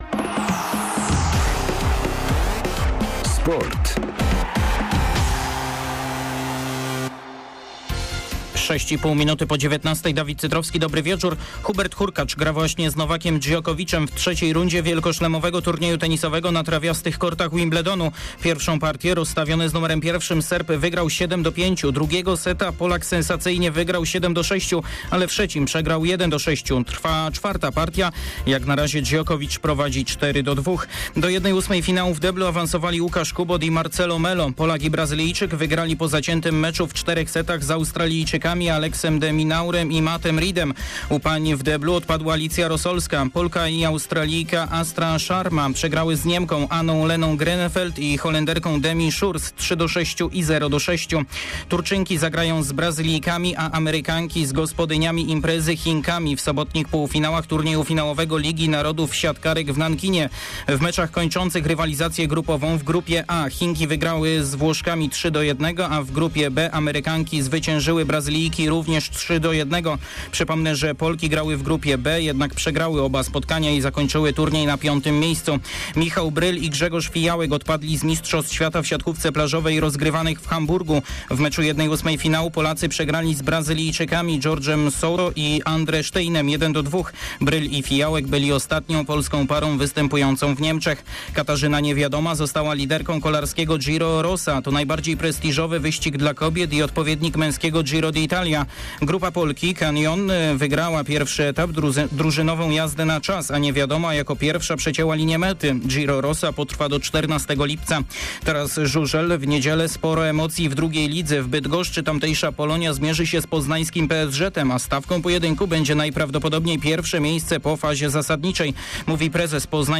05.07. serwis sportowy godz. 19:05